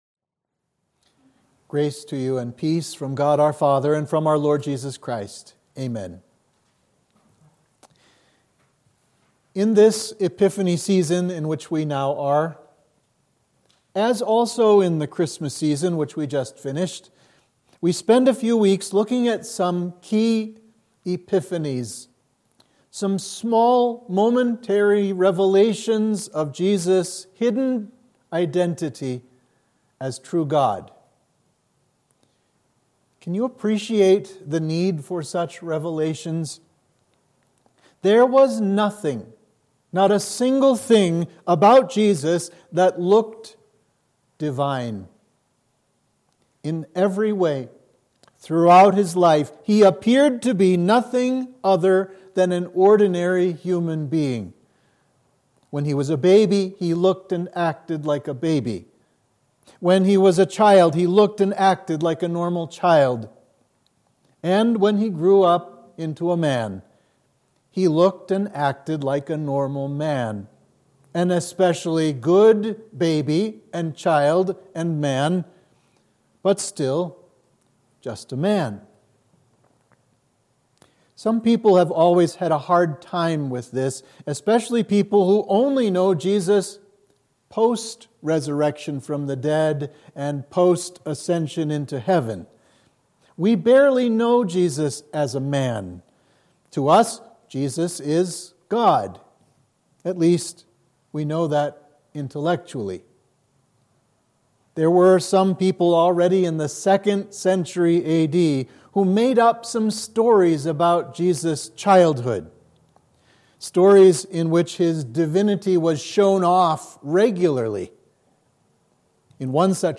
Sermon for Epiphany 1